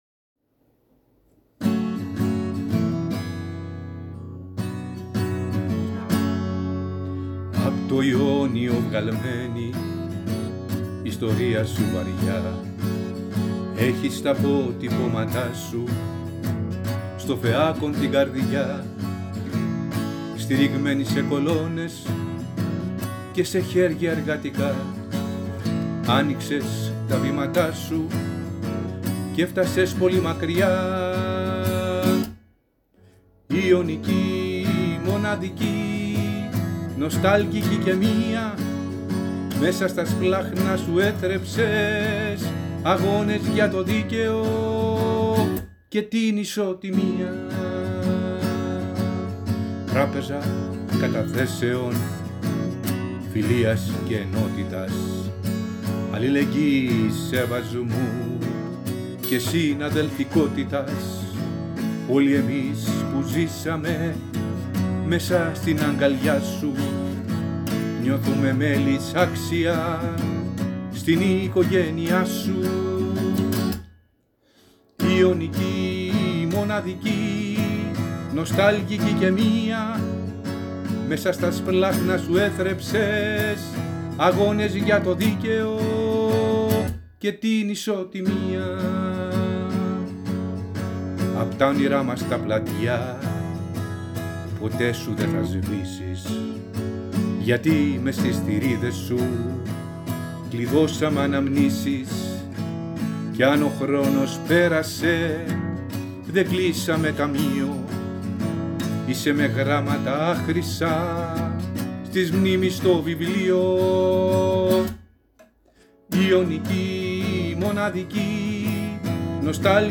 Πρόκειται ουσιαστικά για μία πρώτη, αυθόρμητη εκτέλεση
Η αυθόρμητη αυτή ηχογράφηση μας άρεσε ιδιαιτέρως και είμαστε βέβαιοι ότι θα βελτιωθεί πολύ όταν έρθει η ώρα να ηχογραφήσουμε επαγγελματικά τον Ύμνο.